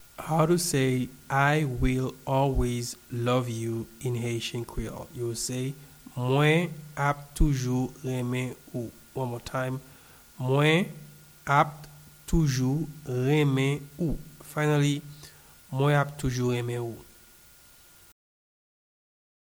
Pronunciation and Transcript:
I-will-always-love-you-in-Haitian-Creole-Mwen-ap-toujou-renmen-ou-pronunciation.mp3